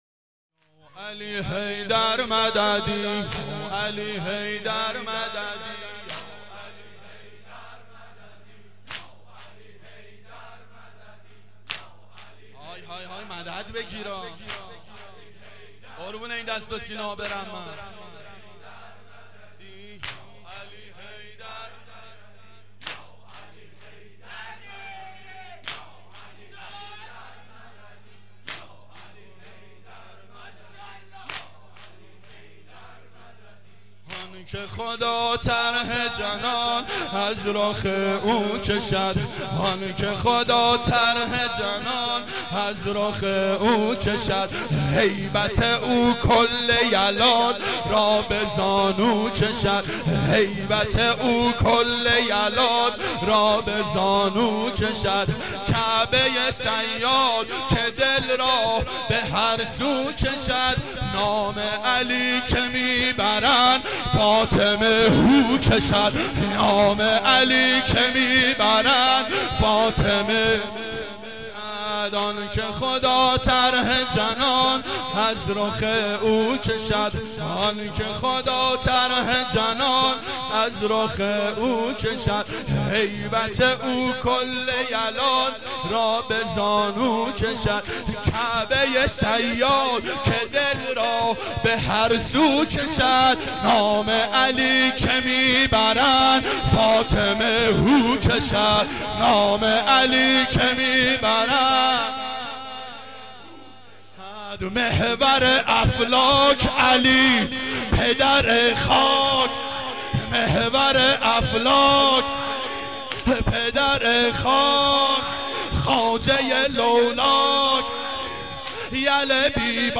گلچین سال 1389 هیئت شیفتگان حضرت رقیه سلام الله علیها